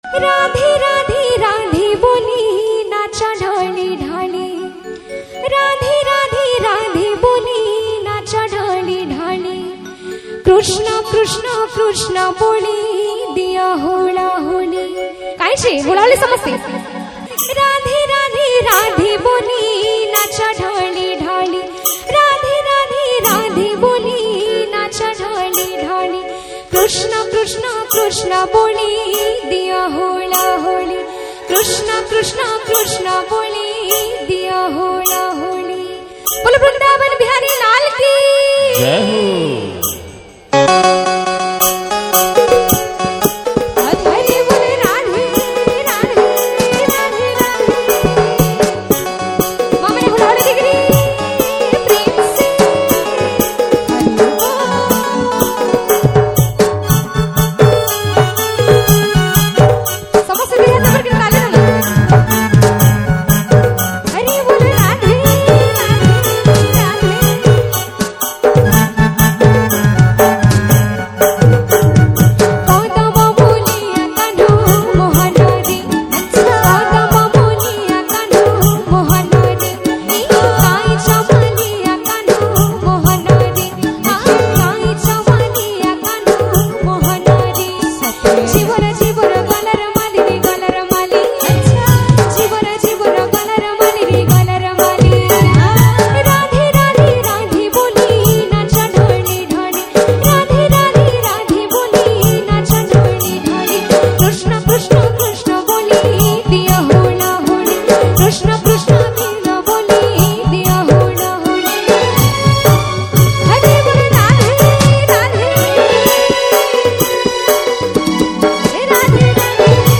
Odia Kirtan Bhajan Songs